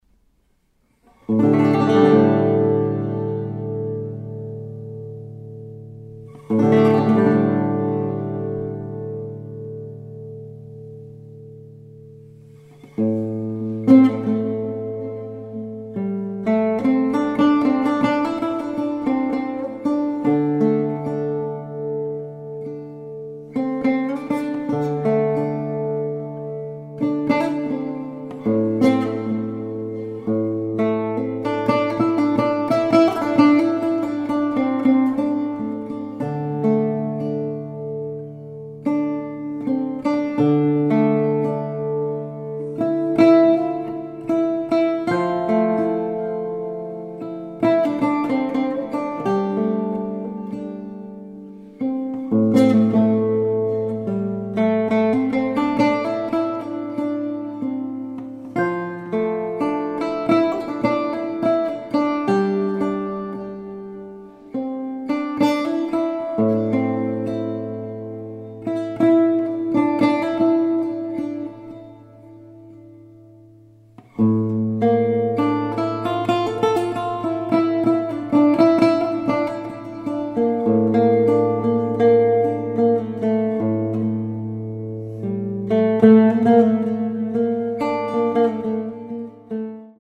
mandora / lute guitar